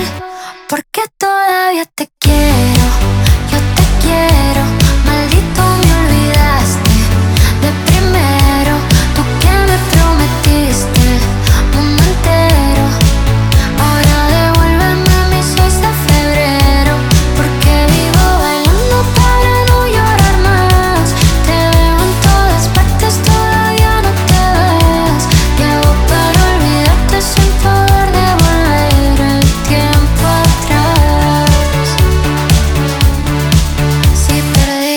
Скачать припев
Pop Latino